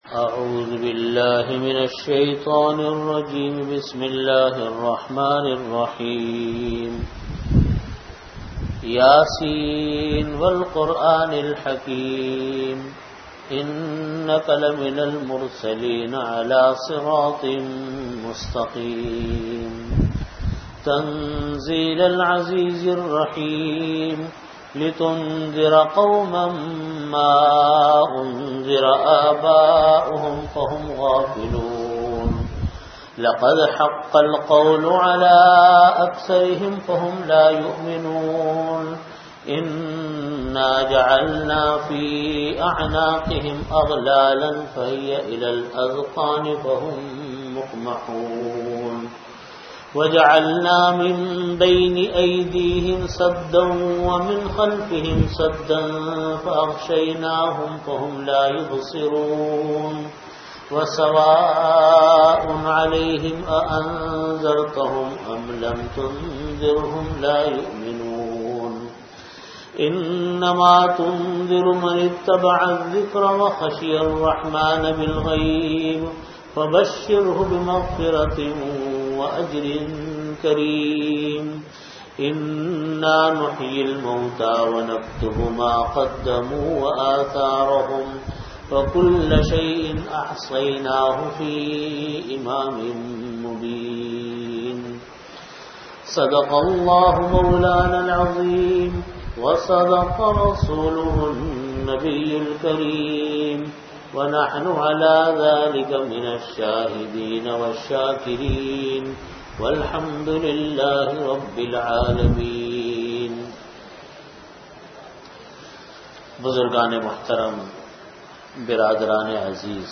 Audio Category: Tafseer
Time: After Asar Prayer Venue: Jamia Masjid Bait-ul-Mukkaram, Karachi